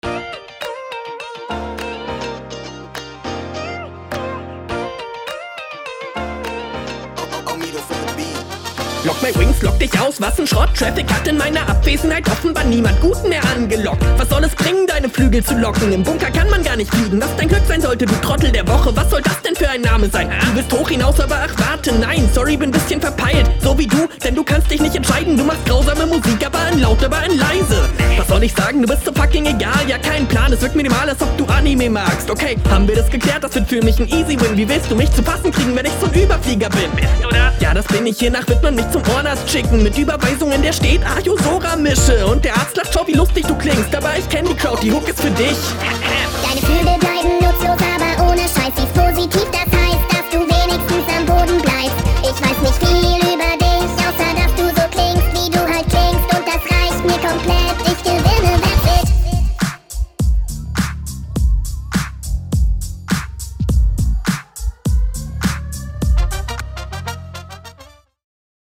Stabil gerappt und vor allem sehr clean gemischt.
Nice punches, Flow und interessante hook lol Feier ich